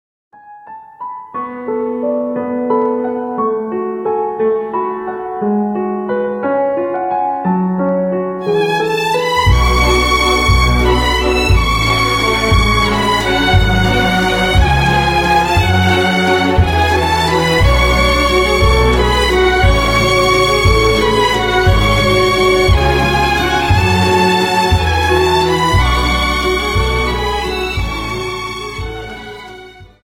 Dance: Viennese Waltz 59